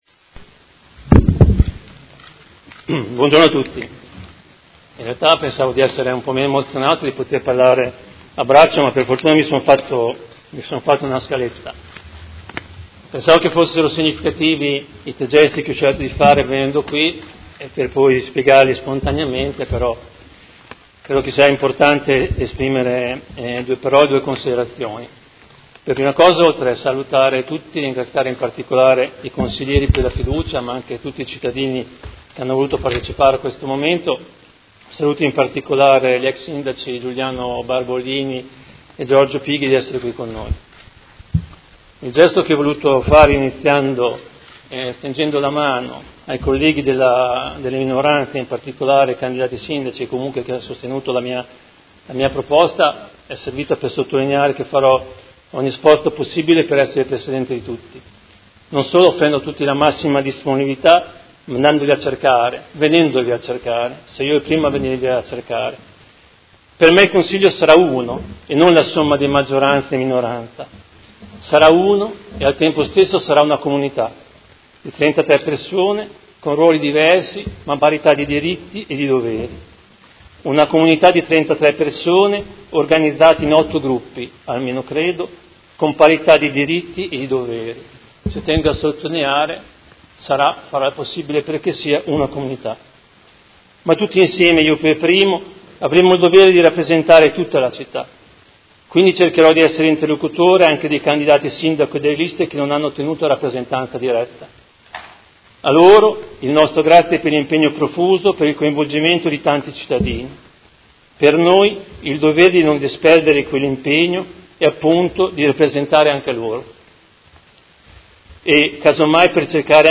Seduta del 13/06/2019 Discorso di insediamento come nuovo Presidente.